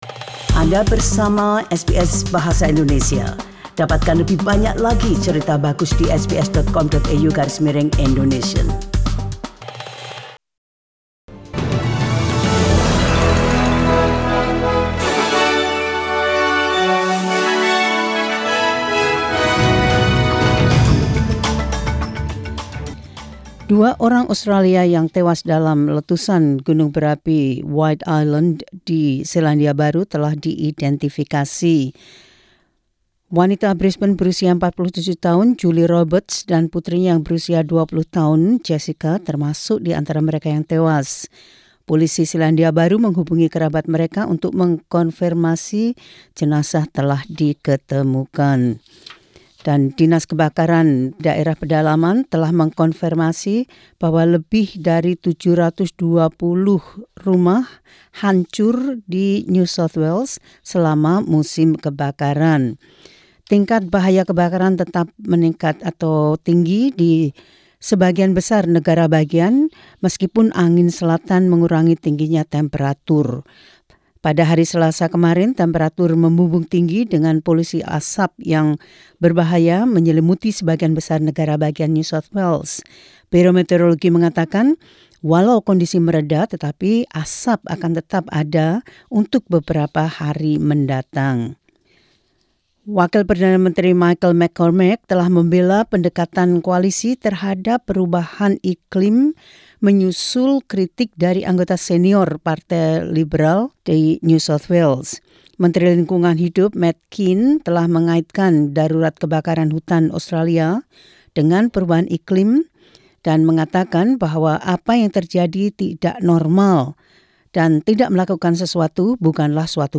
SBS Radio News in Indonesian - 11 Dec 2019.